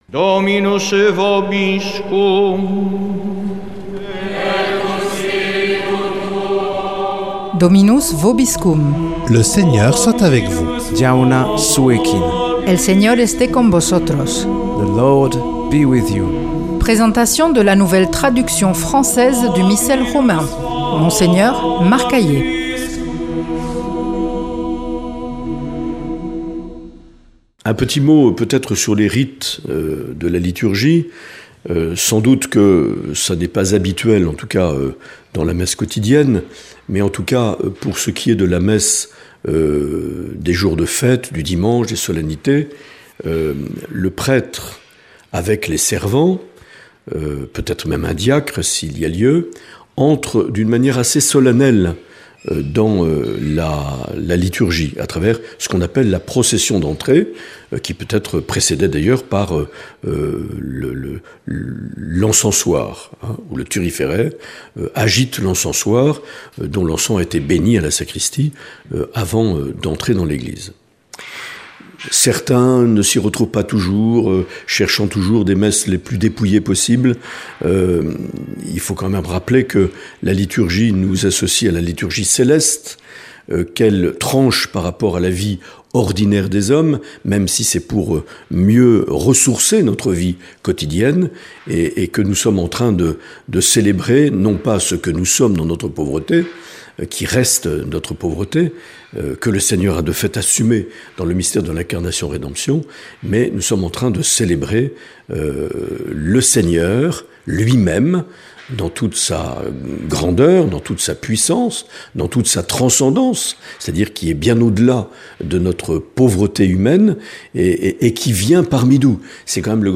Présentation de la nouvelle traduction française du Missel Romain par Mgr Marc Aillet
Monseigneur Marc Aillet